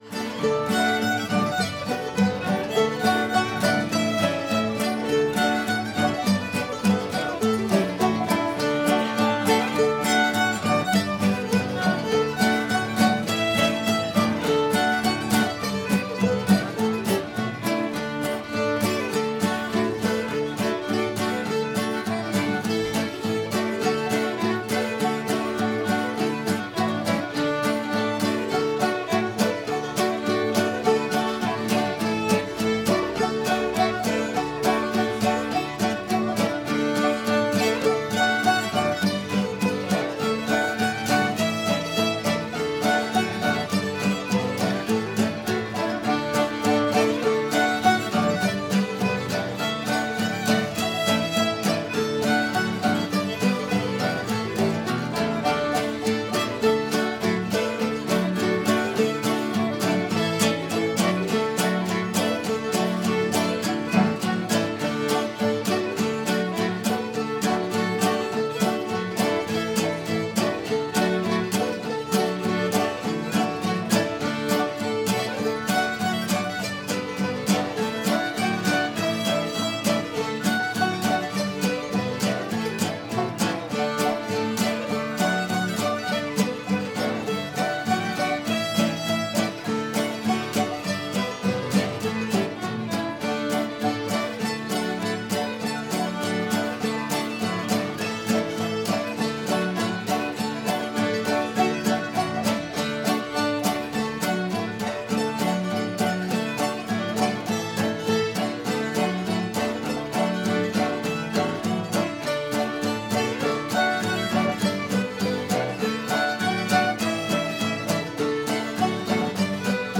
ruffled drawers [D]